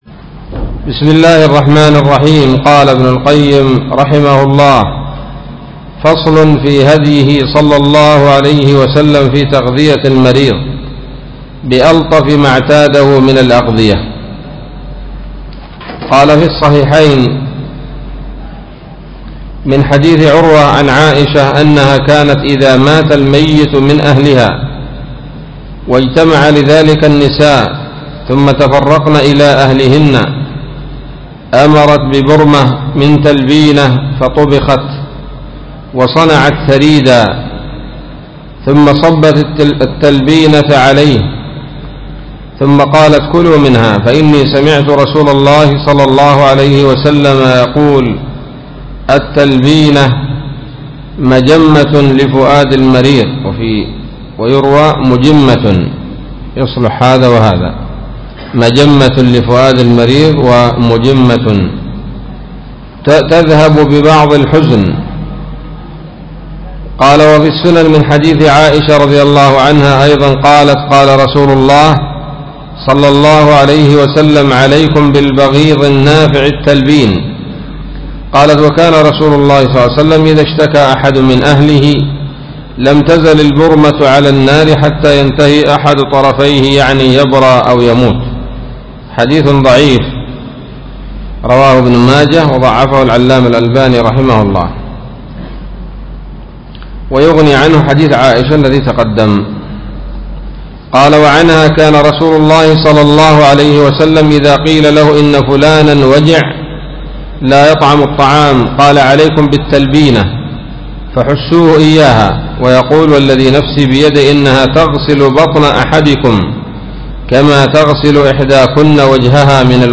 الدرس الثالث والثلاثون من كتاب الطب النبوي لابن القيم